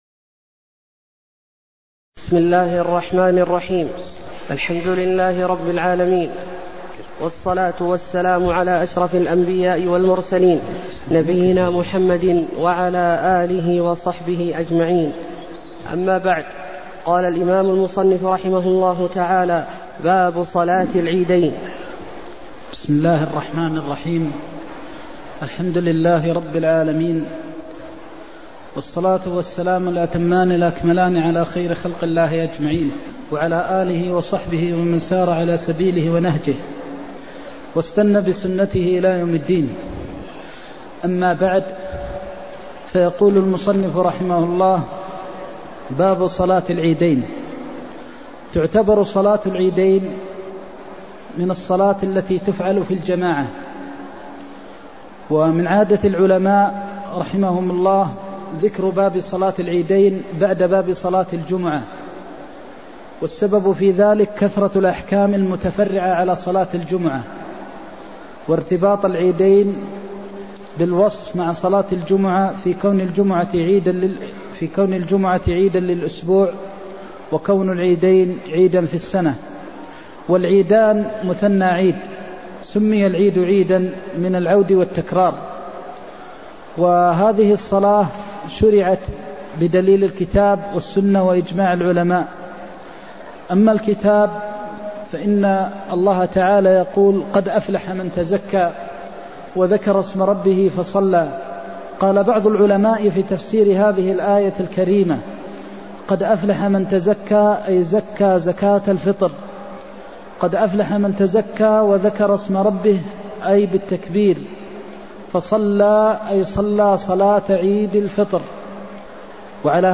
المكان: المسجد النبوي الشيخ: فضيلة الشيخ د. محمد بن محمد المختار فضيلة الشيخ د. محمد بن محمد المختار باب العيدين (16)